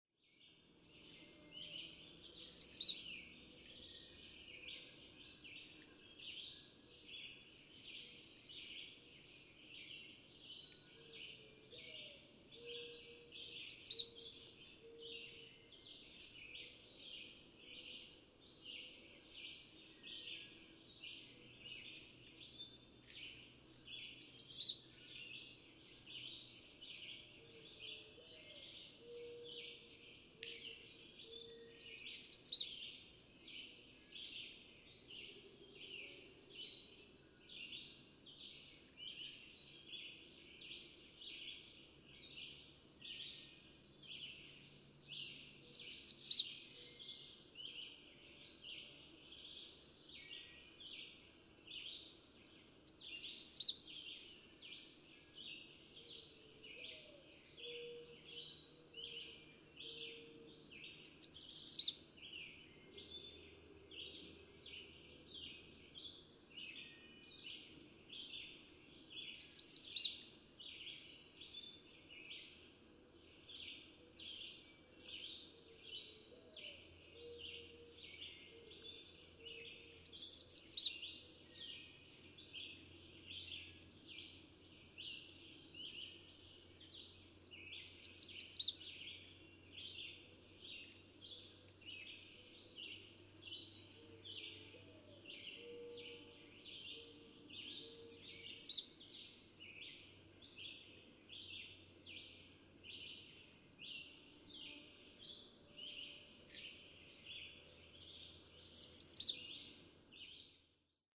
American redstart
Day song of the second male.
Cricket Hill, Conway, Massachusetts.
565_American_Redstart.mp3